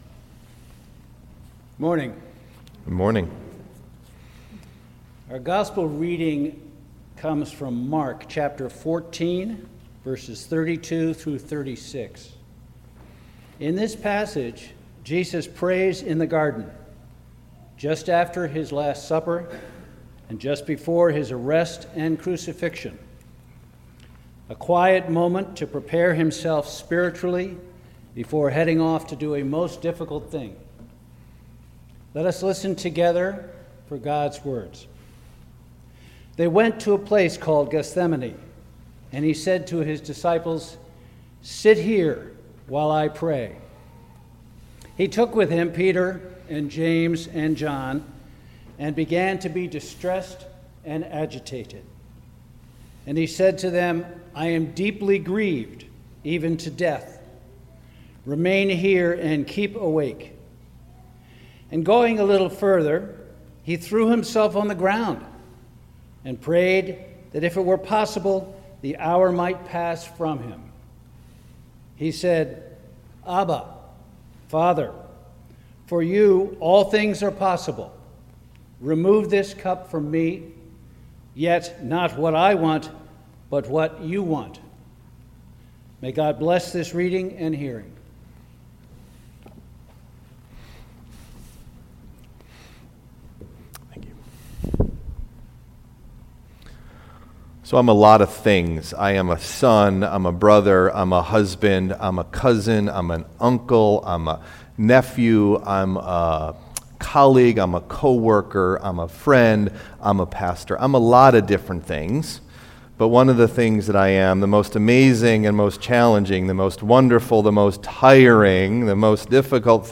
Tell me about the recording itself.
Message Delivered at: Charlotte Congregational Church (UCC)